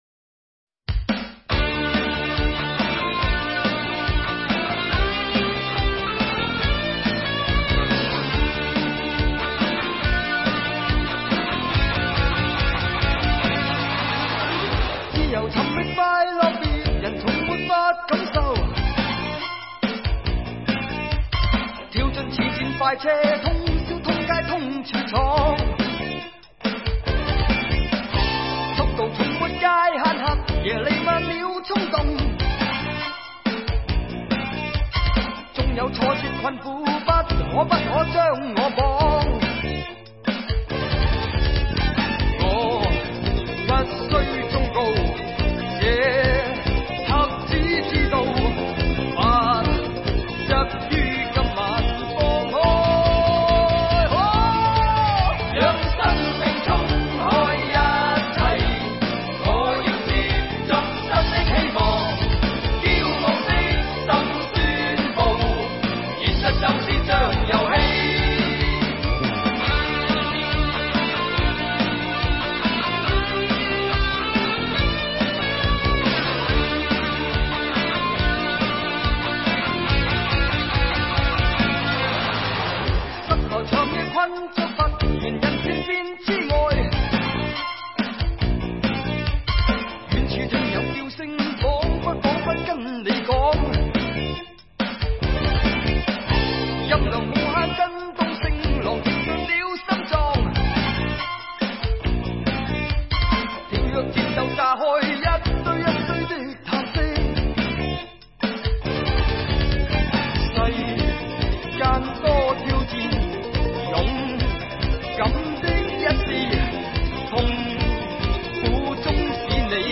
粤语专辑